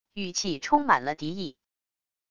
语气充满了敌意wav音频